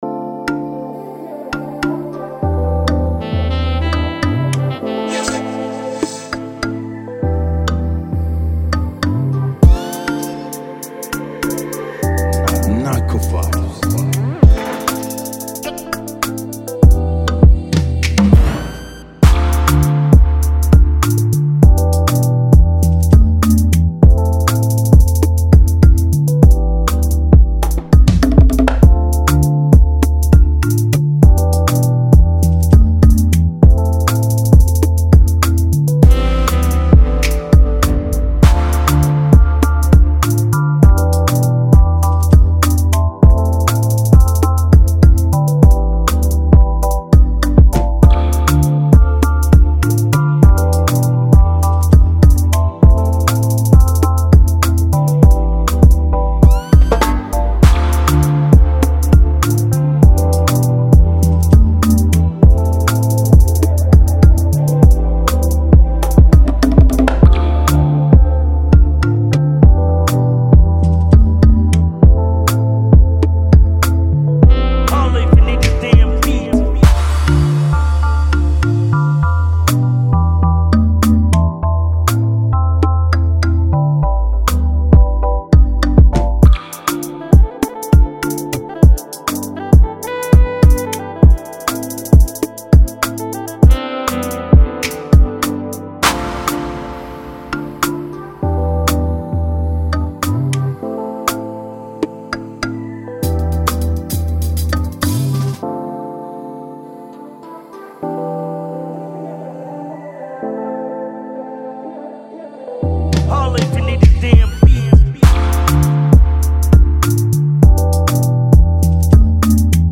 2022-12-20 1 Instrumentals 0
beat instrumental with tempo  100